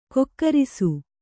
♪ kokkarisu